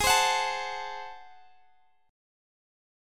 Listen to G#7b9 strummed